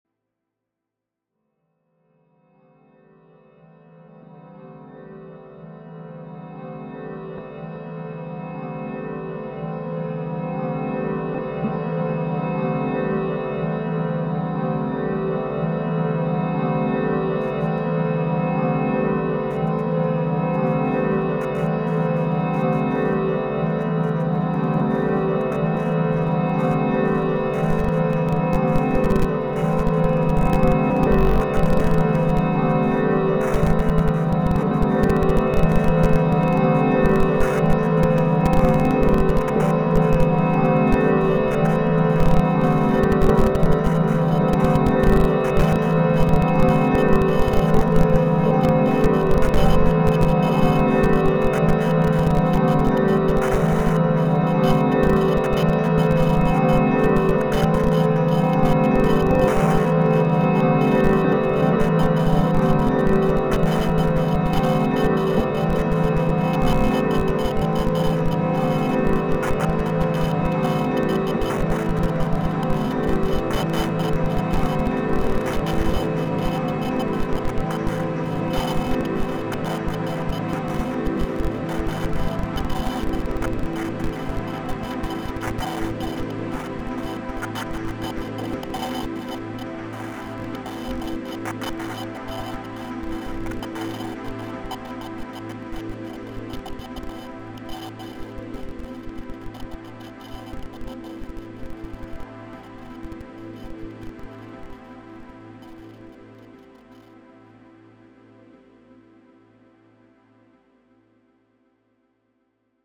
It’s a piano (looped/crossfaded) and pad from the stock library, and a few other bits run through a lot of Degrader.